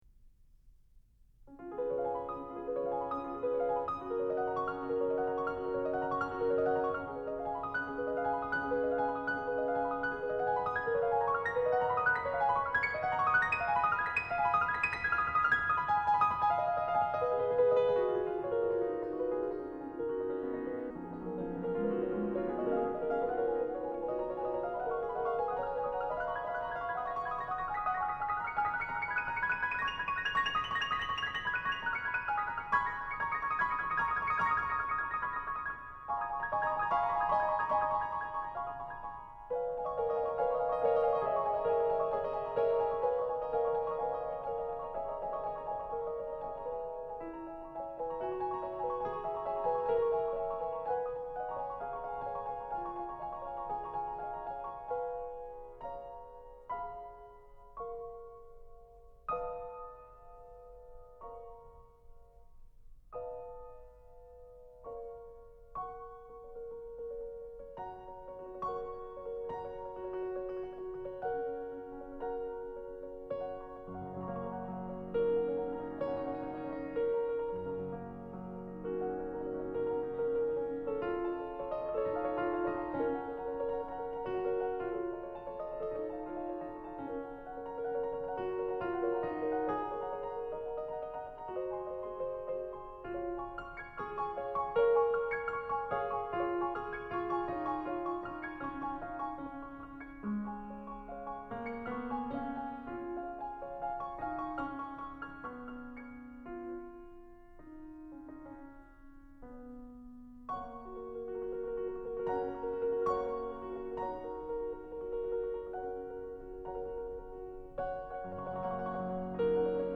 • Franz Liszt – Jeux d’eau à la villa d’Este – J. Bolet, piano – 1983 ****
Les « jeux d’eau à la Villa d’Este » sont une pièce d’une virtuosité peu ostentatoire, qui ont eu une influence certaines sur les compositeurs « impressionnistes » français. La version de ce jour est celle de Jorge Bolet, pianiste cubain qui, au soir de sa carrière, consacra une assez large anthologie à Franz Liszt au détour des années 80, laquelle connut un très grand succès.